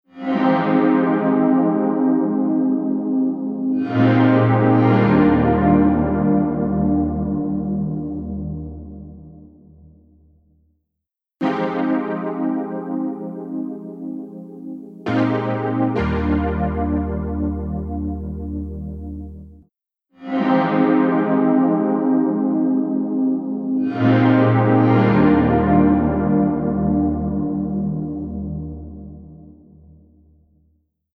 ピッチシフティングを融合した、幻想的なリバーブ
ShimmerVerb | Poly Synth | Preset: Porcelain Glow
ShimmerVerb-Eventide-Poly-Synth-Porcelain-Glow.mp3